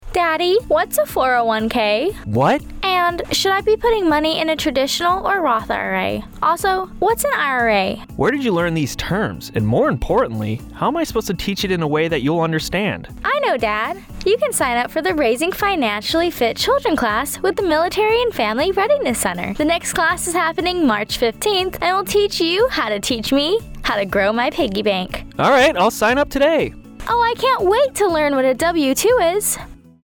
Radio Spot- Financially Fit Children Class